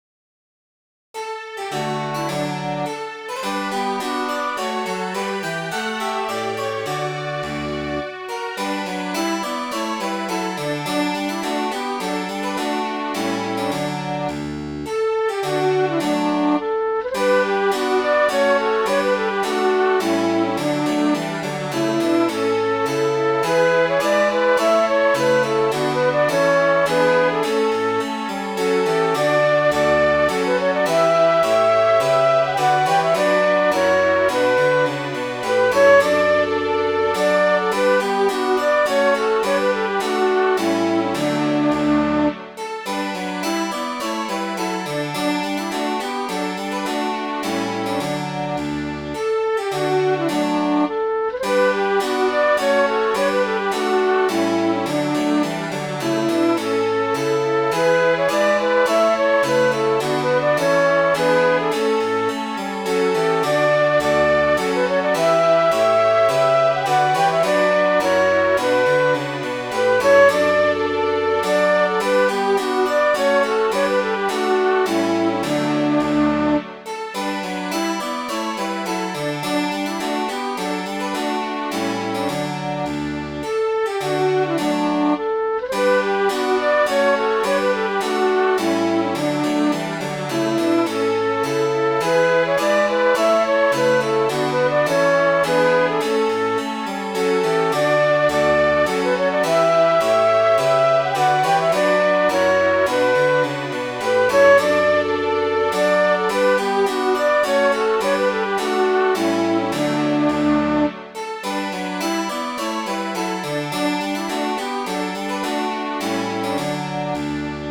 Midi File, Lyrics and Information to Enraptured I Gaze